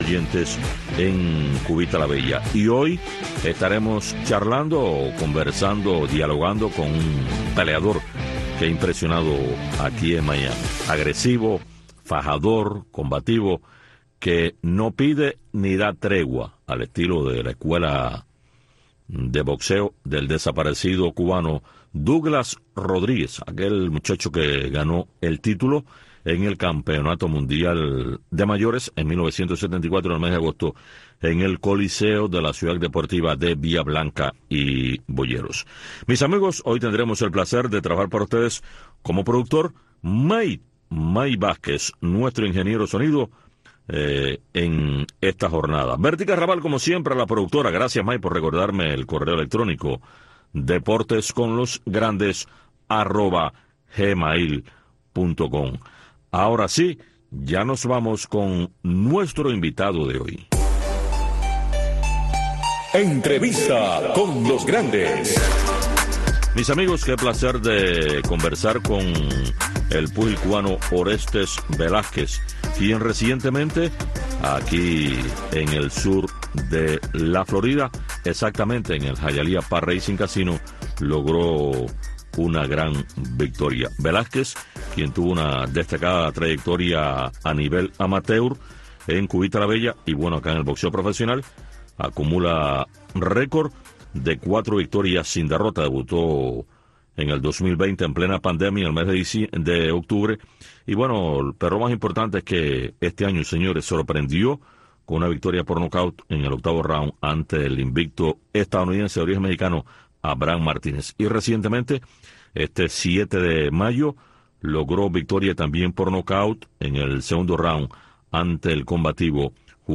Deportes con los Grandes. Un programa de Radio Martí, especializado en entrevistas, comentarios, análisis de los Grandes del deporte.